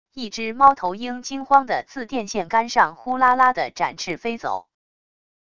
一只猫头鹰惊慌的自电线杆上呼啦啦的展翅飞走wav音频